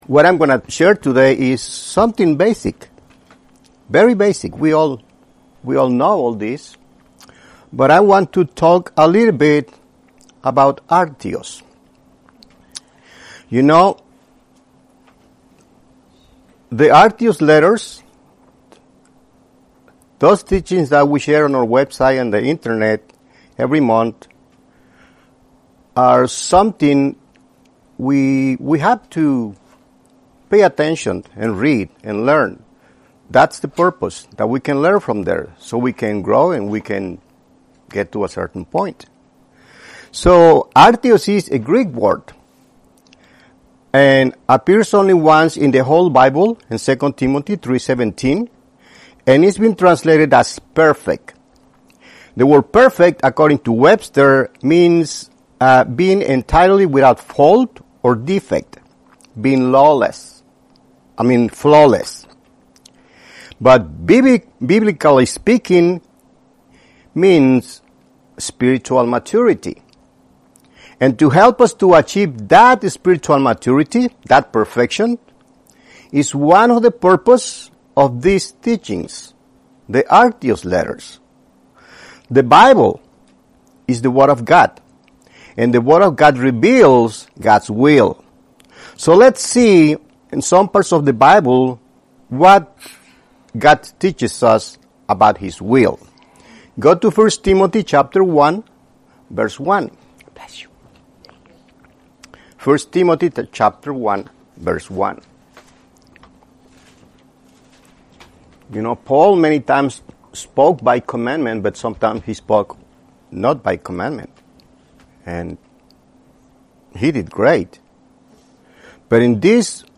Audio Teaching